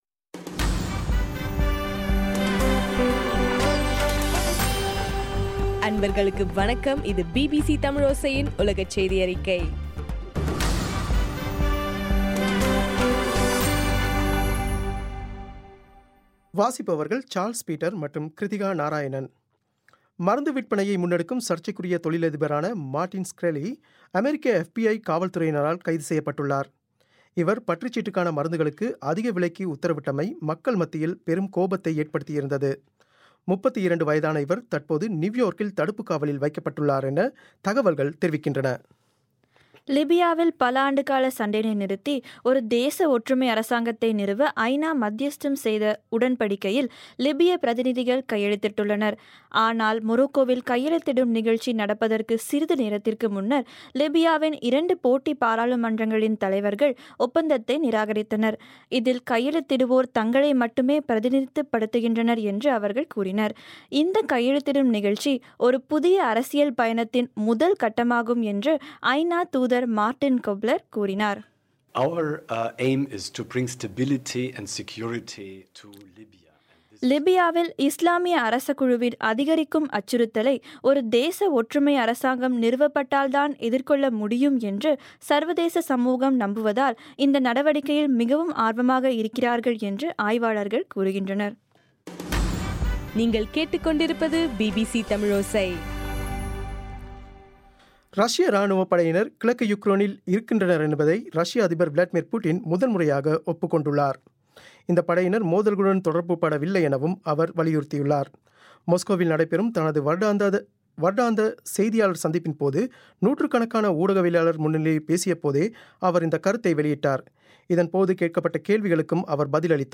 டிசம்பர் 17, 2015 பிபிசி தமிழோசையின் உலகச் செய்திகள்